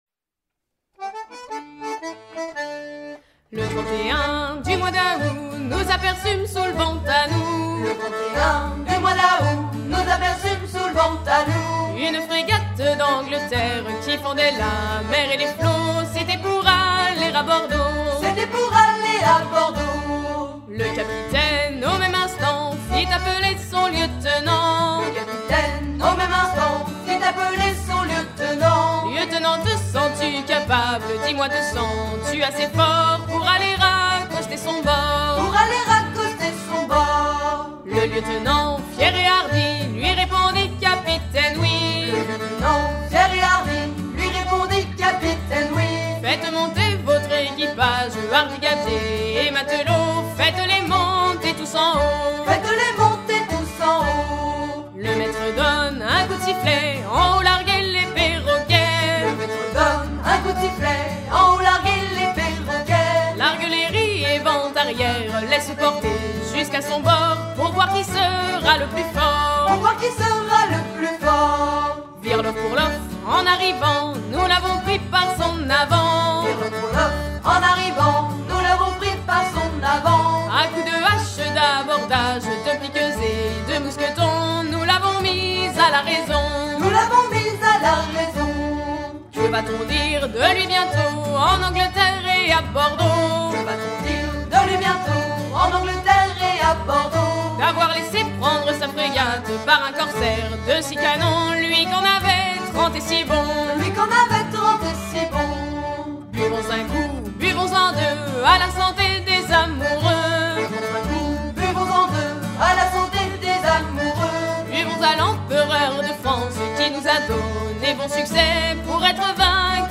gestuel : à virer au cabestan
Genre strophique
Pièce musicale éditée